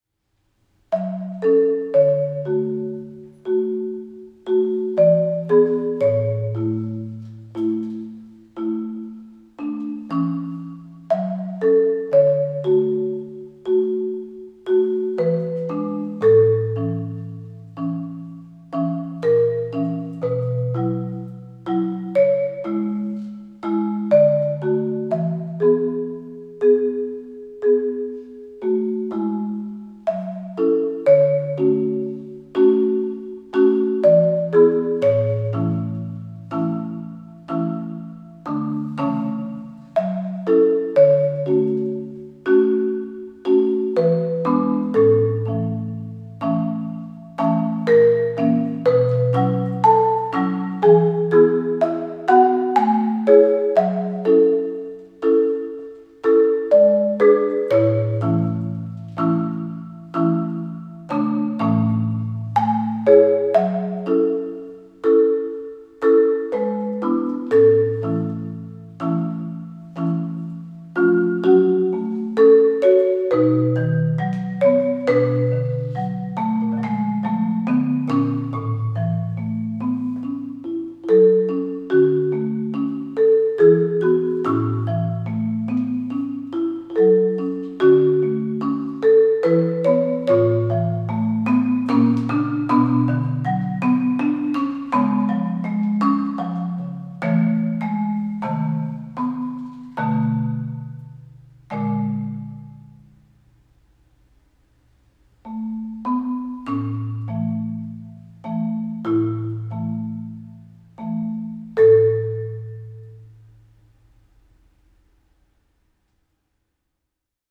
Voicing: Marimba Solo